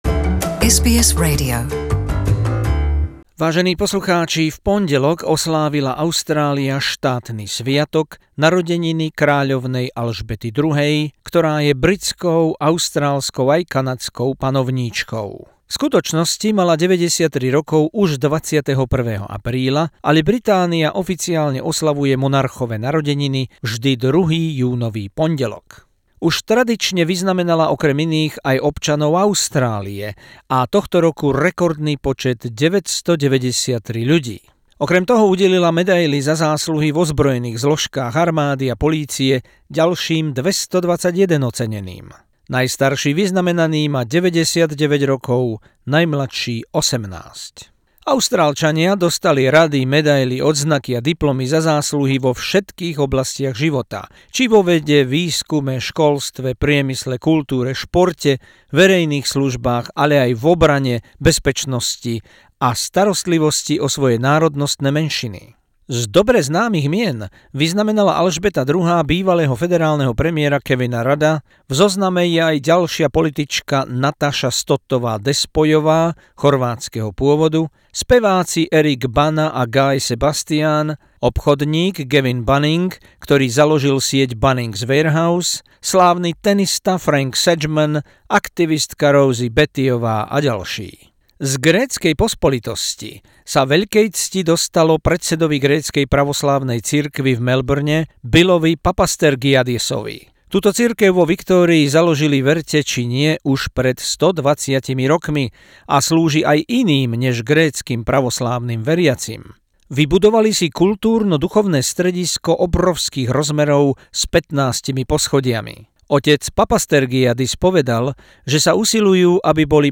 Rozšírená správa o štátnom sviatku, narodeninách kráľovnej Alžbety II., panovníčky Británie, Kanady, Austrálie a ďalších 13 krajín Britského spoločenstva. Pri tejto príležitosti udelila vyznamenania rekordnému počtu vyše 1000 Austrálčanov.